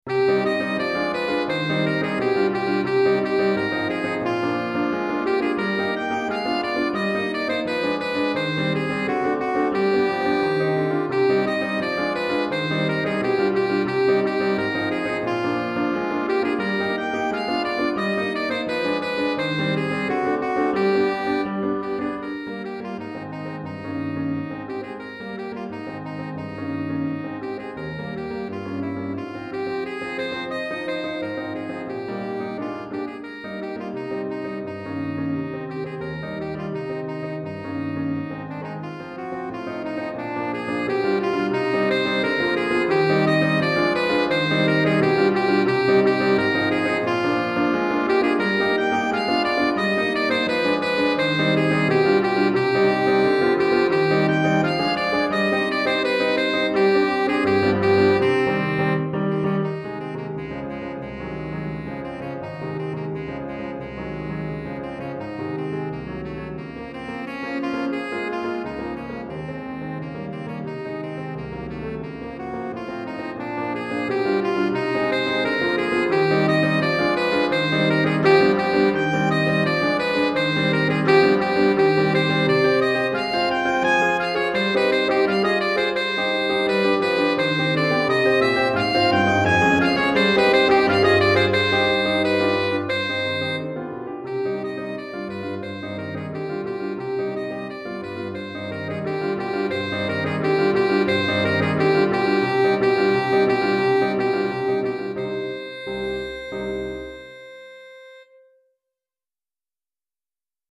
Saxophone Alto et Piano